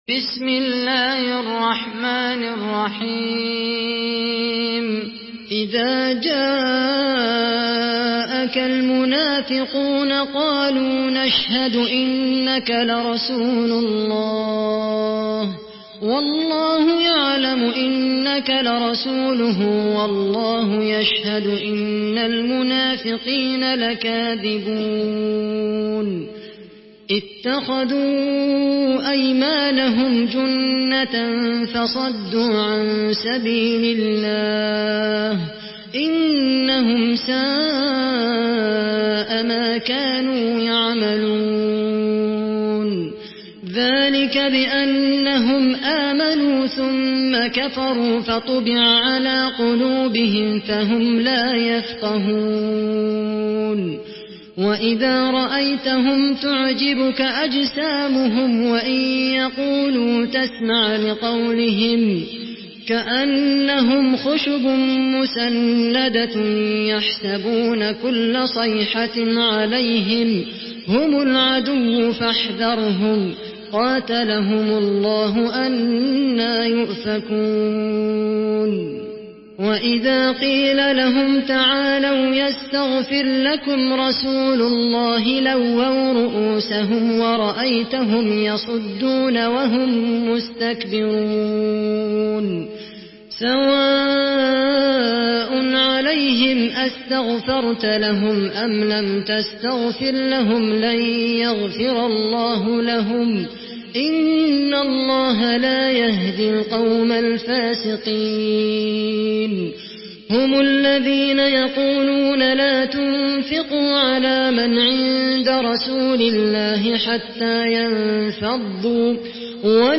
Sourate Al-Munafiqun MP3 à la voix de Khaled Al Qahtani par la narration Hafs
Une récitation touchante et belle des versets coraniques par la narration Hafs An Asim.
Murattal Hafs An Asim